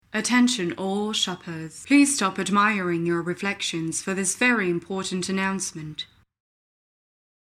Voix off
Podcast - accent anglais